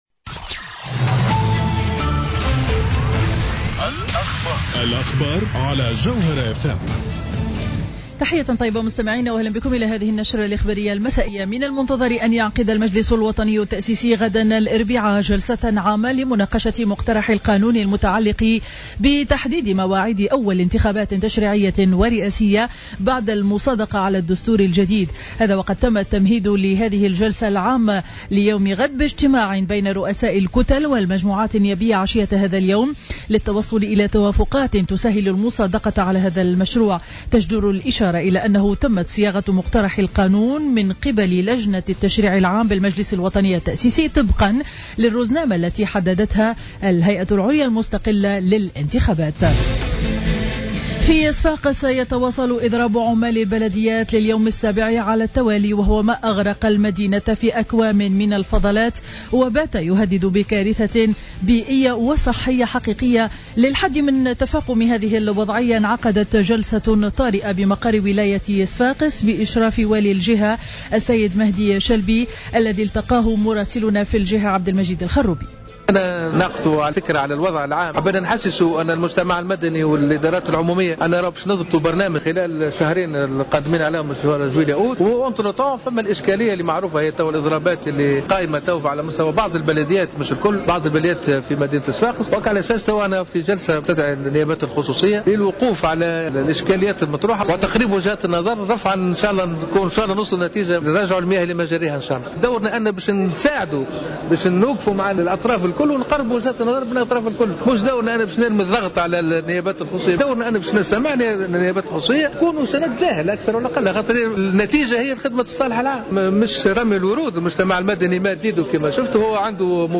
نشرة أخبار السابعة مساء ليوم الثلاثاء 24-06-14